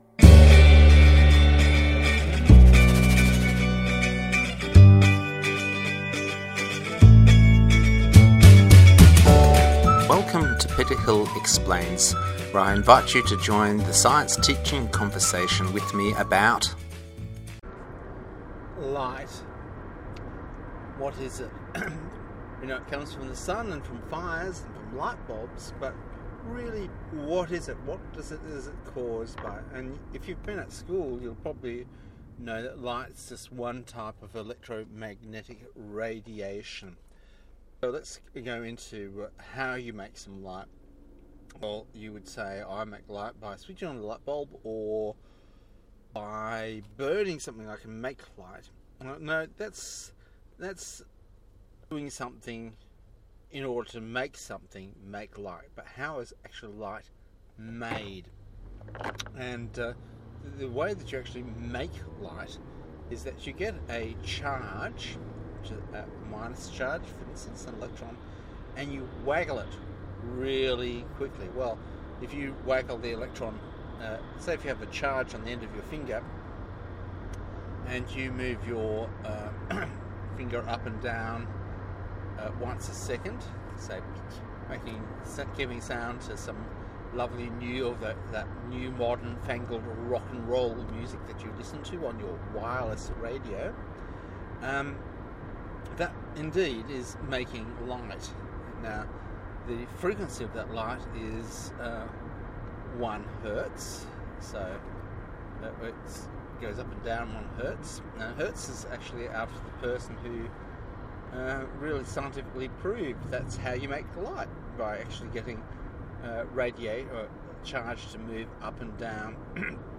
A podcast as I drive. Light is made by anything with an antenna.